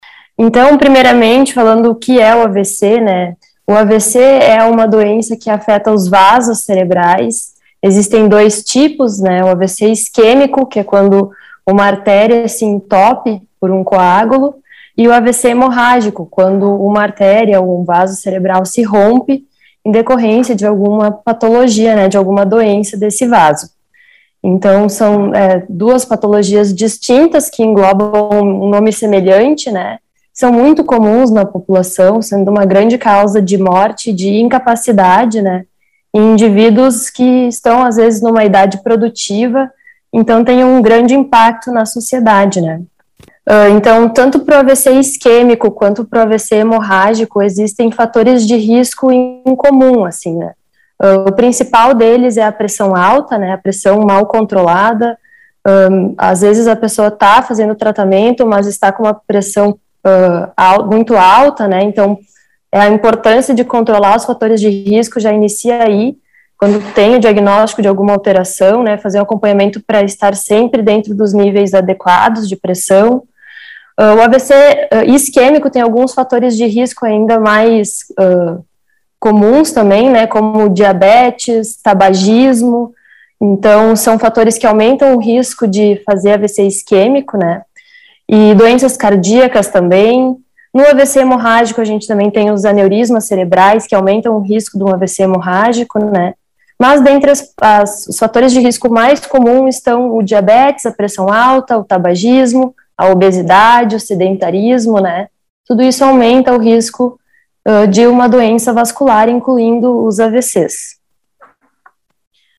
neurologista fala a respeito.